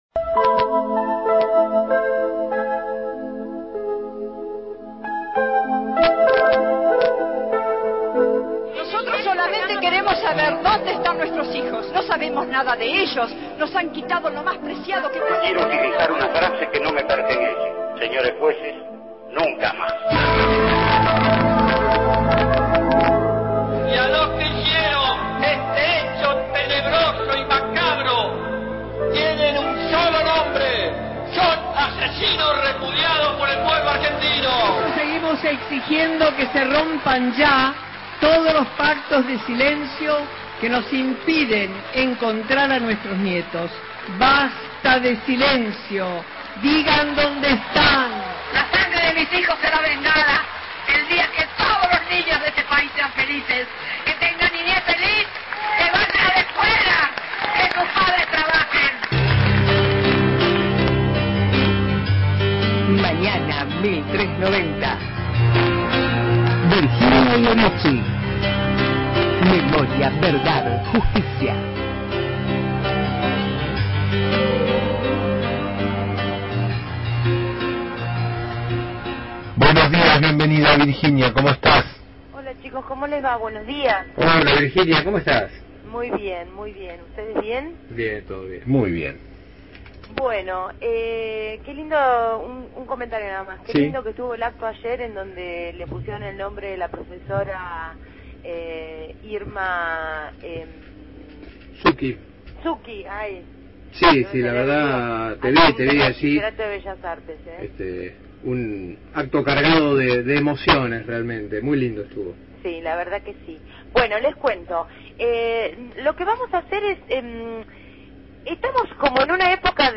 realizó su habitual columna de derechos humanos en «Mañana 1390»